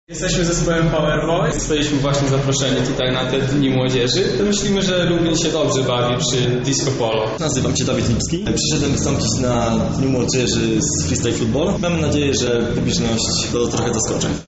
O przygotowanym na tę okazję programie mówią sami uczestnicy